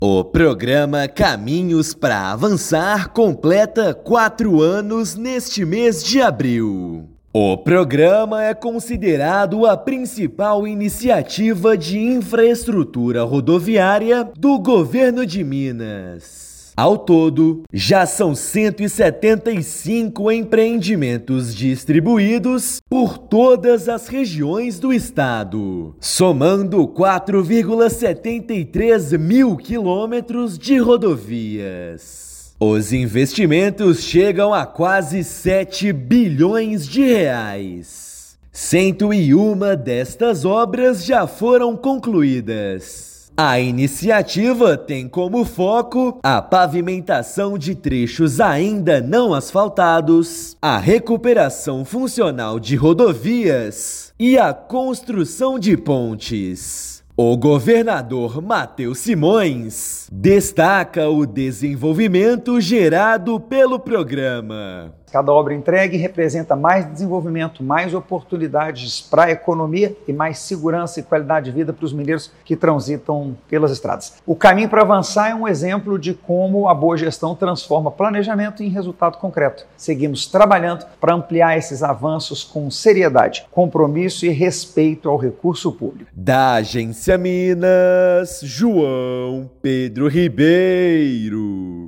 [RÁDIO] Programa Caminhos pra Avançar completa quatro anos com R$ 6,73 bilhões investidos
Mais de 3 mil quilômetros, entre obras de construção e restauração de pavimento, já foram entregues à população. Ouça matéria de rádio.